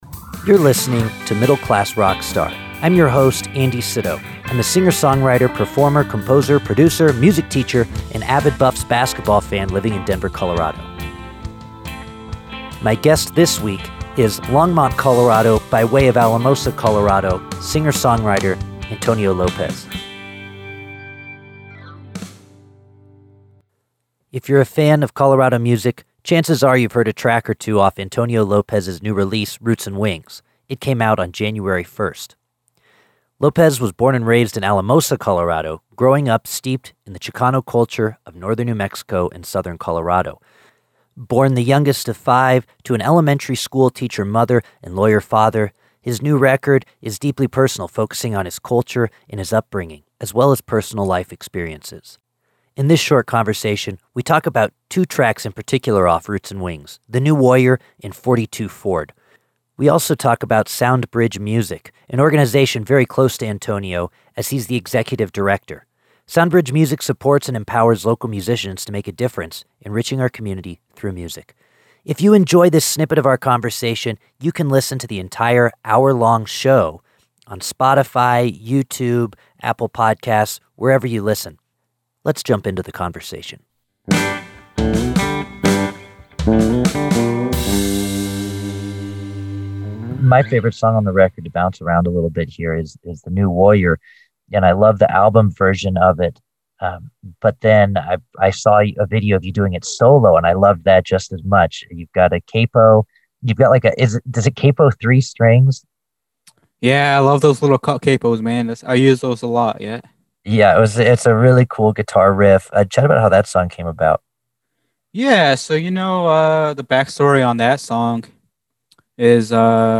Every week or so I feature a 7 to 8 minute segment featuring  a conversation